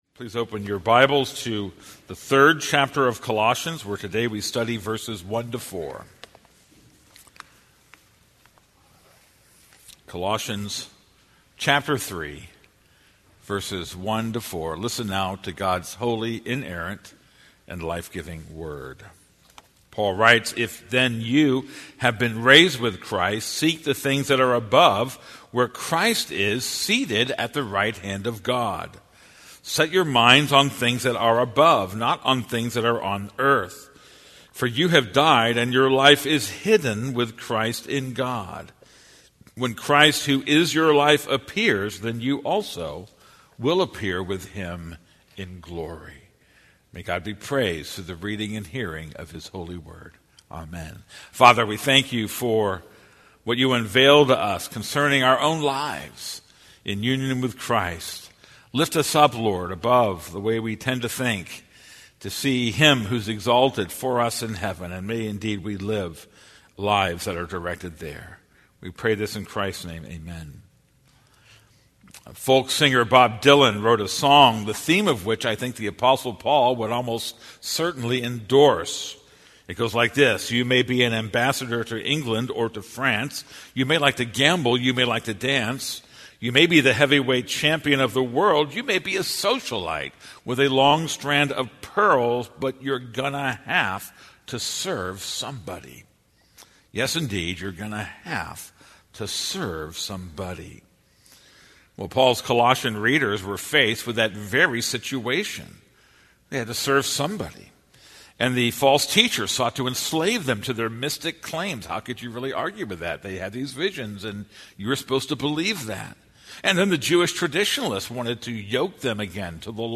This is a sermon on Colossians 3:1-4.